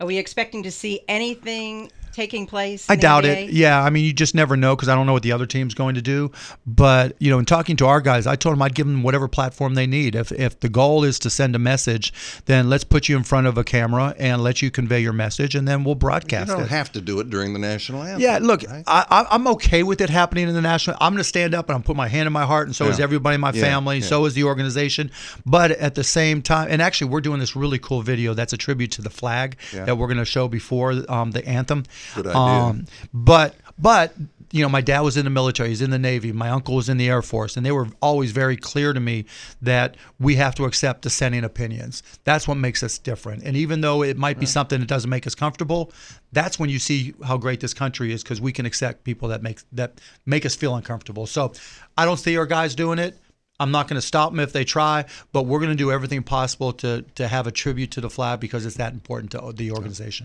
DALLAS (WBAP/KLIF News) — Dallas Mavericks’ owner Mark Cuban joined WBAP and KLIF in studio Monday morning to discuss a variety of topics, including the job President Trump is doing, whether or not he will run for President in 2020, the national anthem kneeling controversy, and of course, the 2017-2018 Mavericks season.